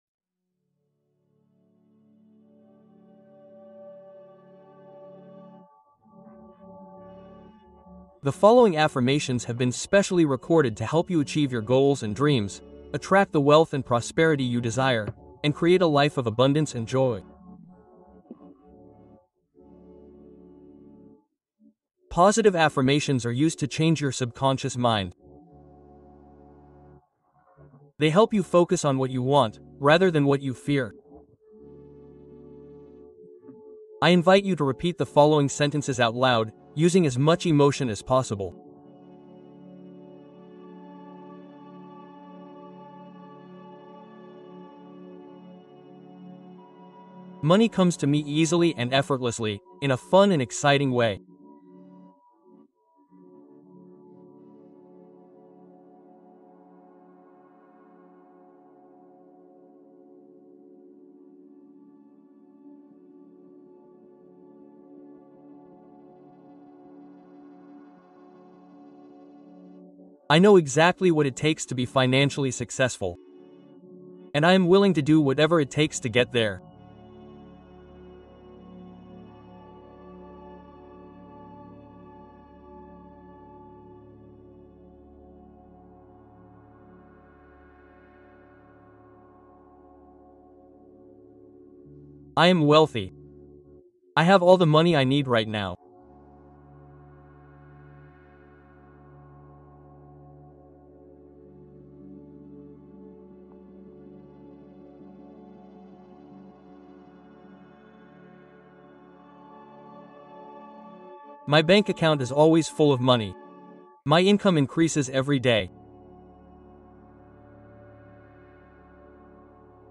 Hypnose pour manifester ses rêves